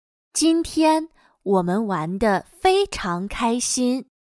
今天我们玩得非常开心。/Jīntiān wǒmen wán dé fēicháng kāixīn./Nos lo pasamos muy bien hoy.